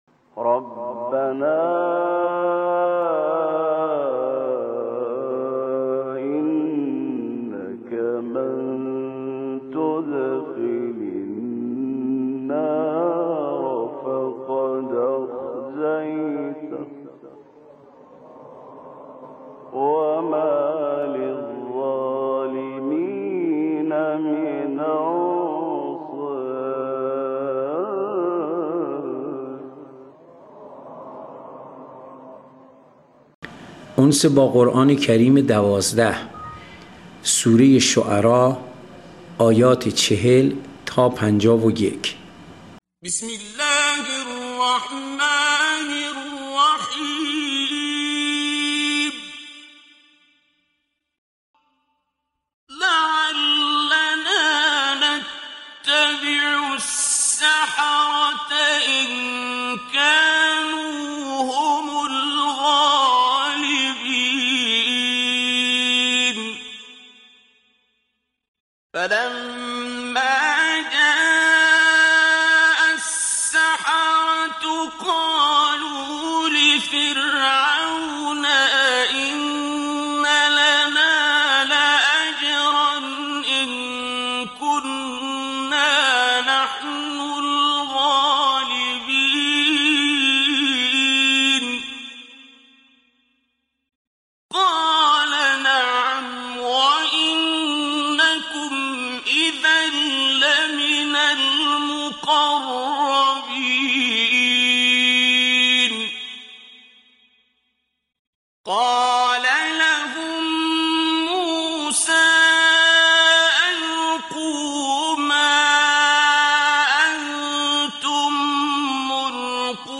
قرائت آیات آیات 41 الی 50 سوره شعراء